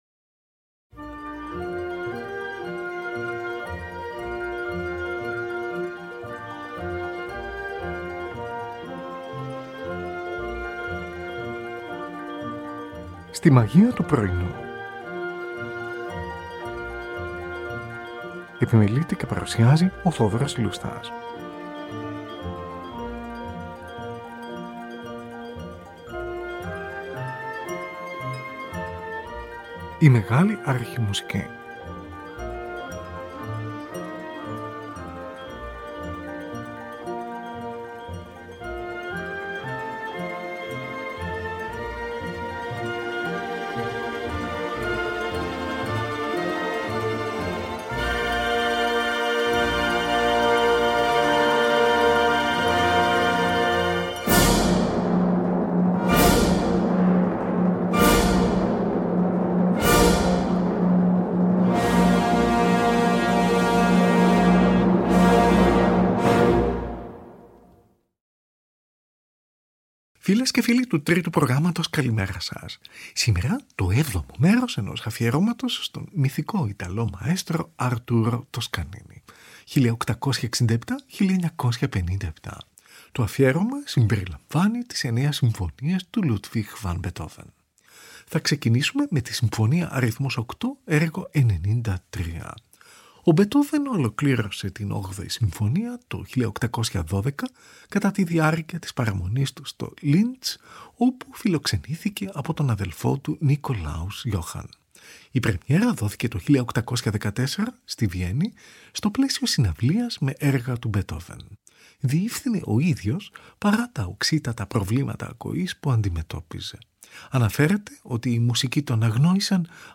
Tη Συμφωνική του NBC διευθύνει ο Arturo Toscanini. Zωντανή ραδιοφωνική μετάδοση, στις 25 Νοεμβρίου 1939.
Τη Συμφωνική του BBC διευθύνει ο Arturo Toscanini. Zωντανή ηχογράφηση στις 3 Ιουνίου 1935, από συναυλία στο Queen’s Hall του Λονδίνου, μόλις 16 μήνες μετά τον θάνατο του Elgar .